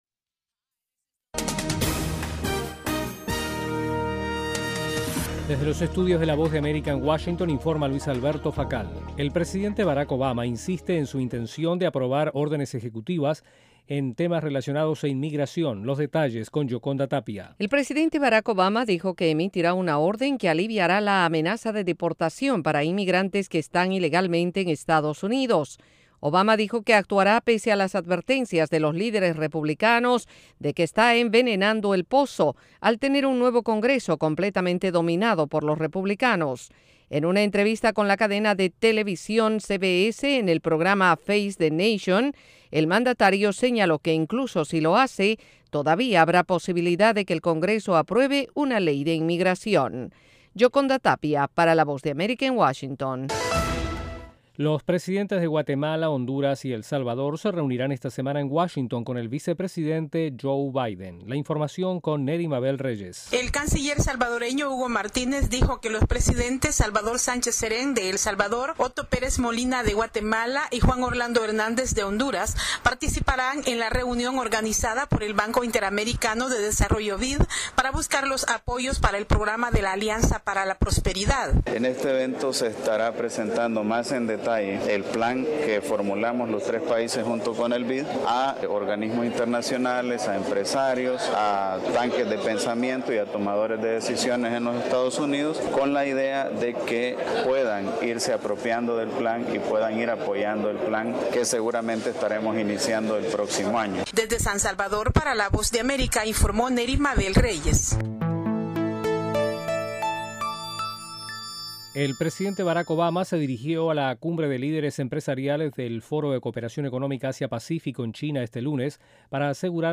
En cinco minutos de duración, Informativo VOASAT ofrece un servicio de noticias que se transmite vía satélite desde los estudios de la Voz de América.